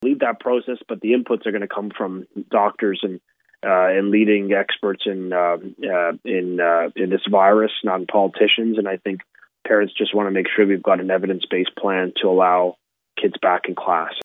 Minister of Education Stephen Lecce
Stephen-Lecce.mp3